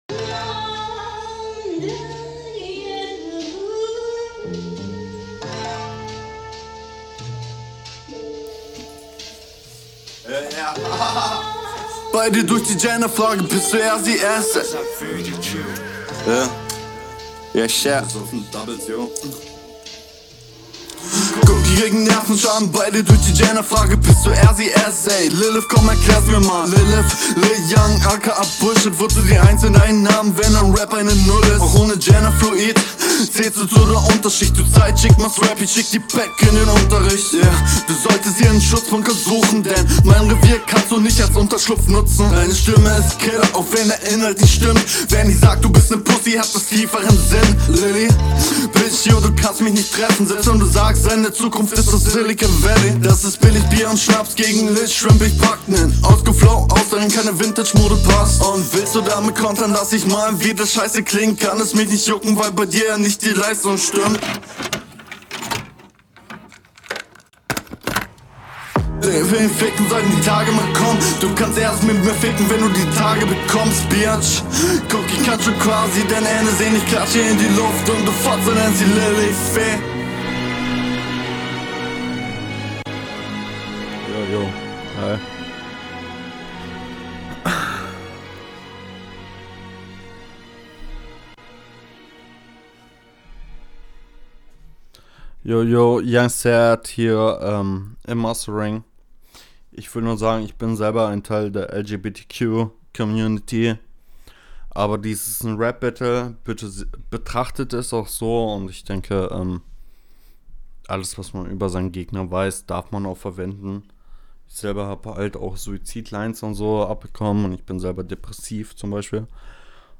Auch wieder gut gerappt, nur von der Aussprache an einigen Stellen nicht ganz sauber.